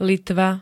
Zvukové nahrávky niektorých slov
pzo6-litva.ogg